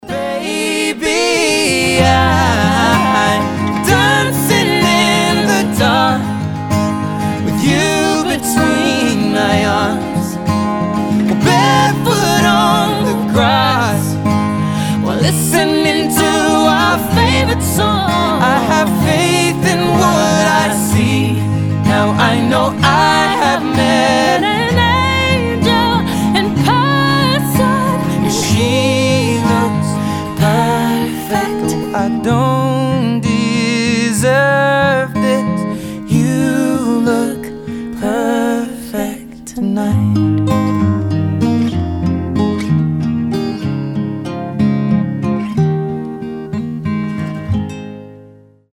• Качество: 320, Stereo
поп
спокойные
дуэт
ballads
акустическая гитара
мужской и женский вокал